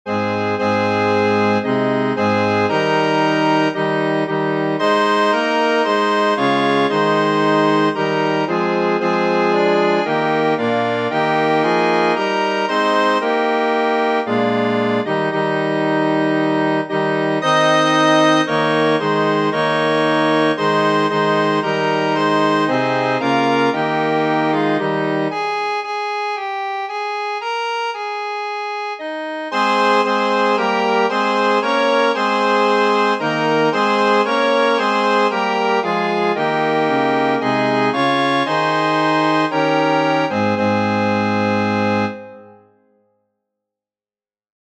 Flauta Letra: PowerPoint , PDF Música: PDF , MIDI , MXL 1.